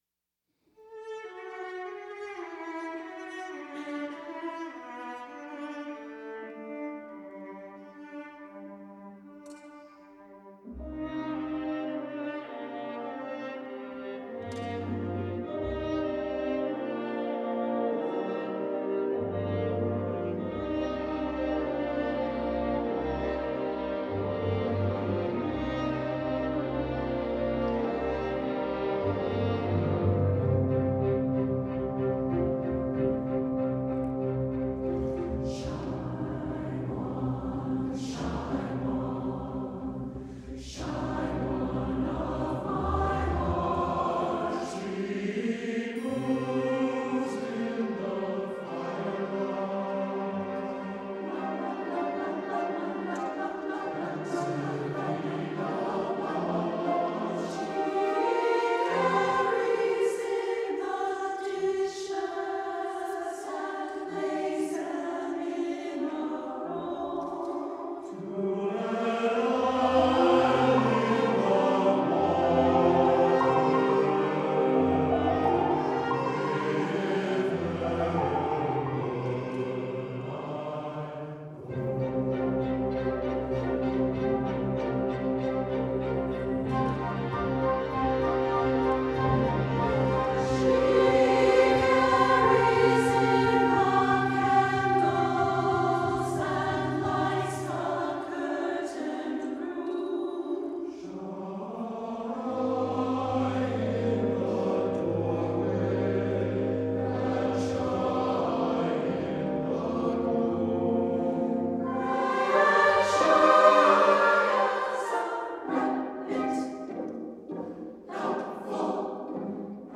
for SATB Chorus and Chamber Orchestra (2005)